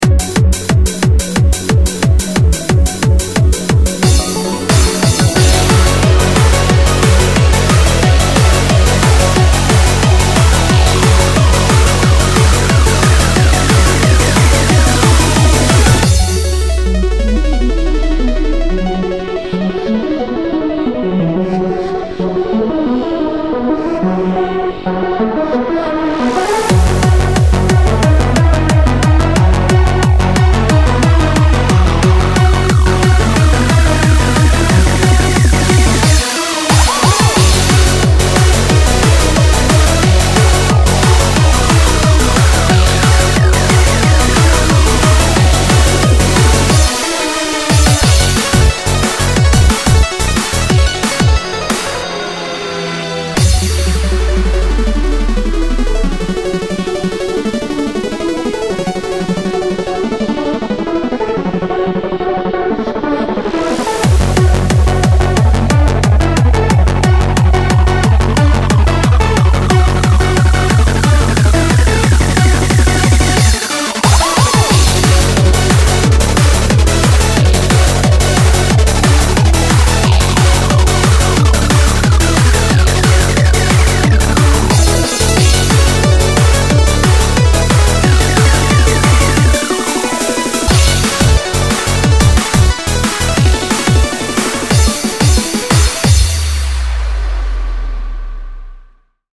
BPM180
uptempo jam